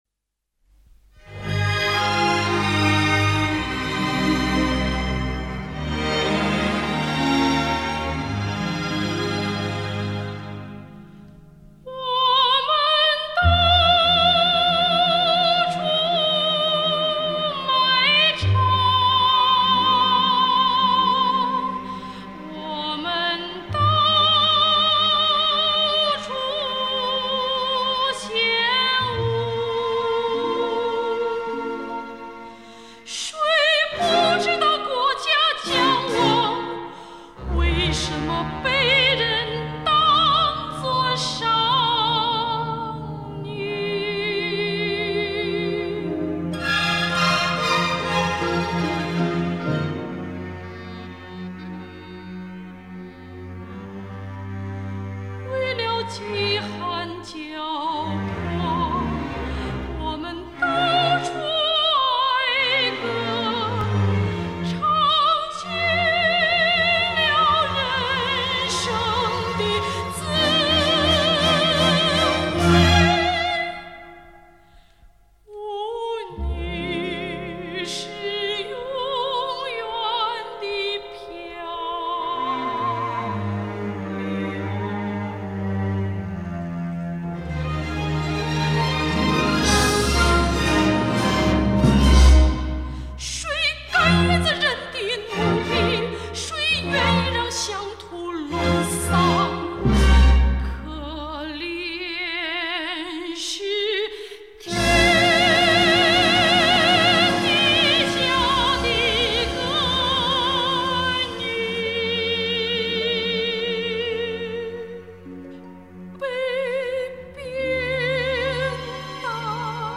这首歌正是她在卖艺时演唱的，情绪深沉而悲痛，但却哀而不伤，怨中有怒，富有内在的、戏剧性的、鼓舞人们起来斗争的力量。